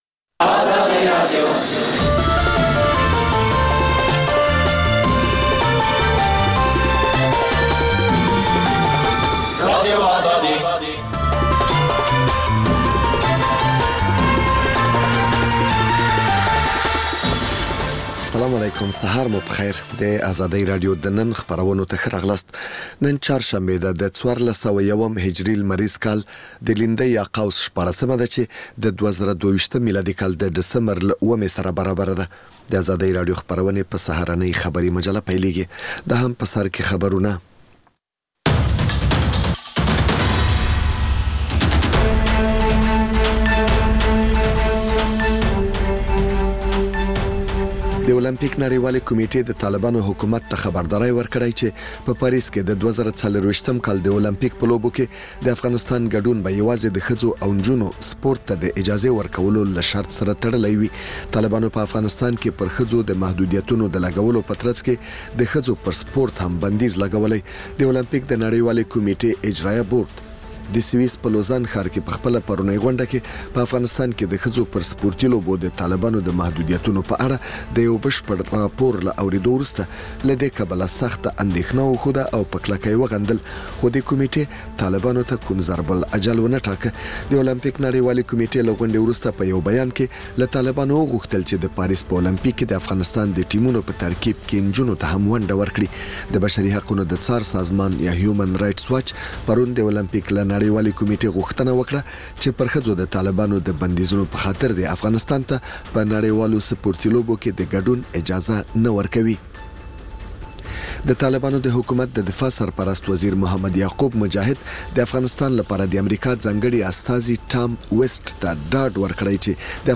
پخش زنده - رادیو آزادی